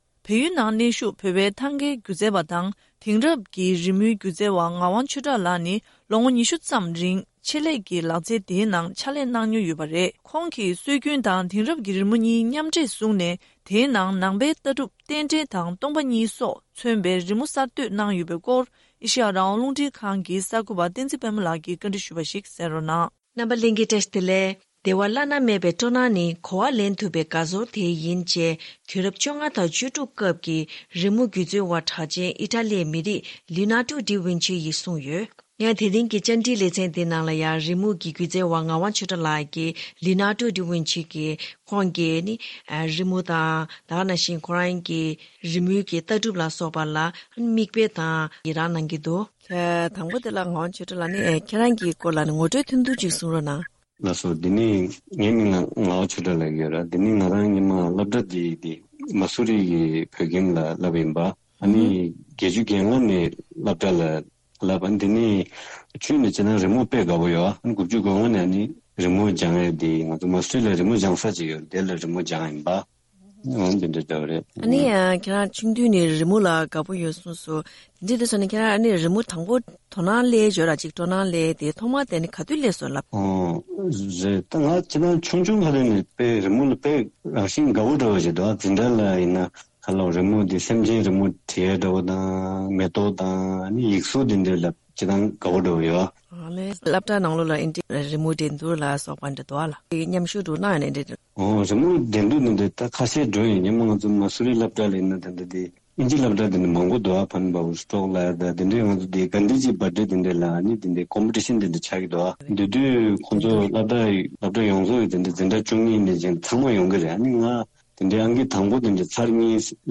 བཅར་འདྲི་བྱས་བར་གསན་རོགས།